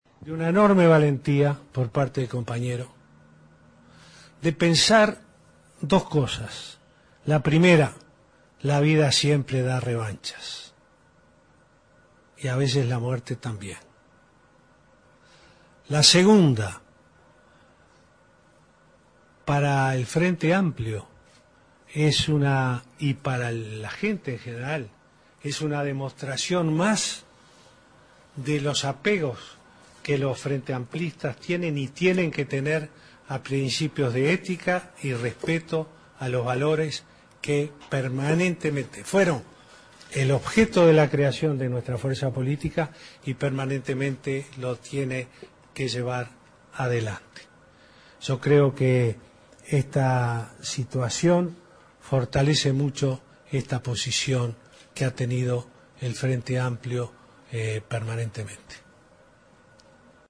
El presidente de la República, Tabaré Vázquez, dedicó la conferencia de prensa posterior al Consejo de Ministros para referirse a la renuncia presentada por el vicepresidente, Raúl Sendic, ante el Plenario del Frente Amplio.